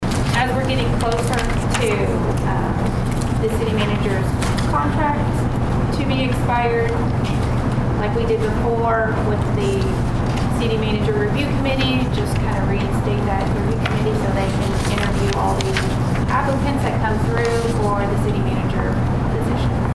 Mayor Susan Bayro goes into more detail.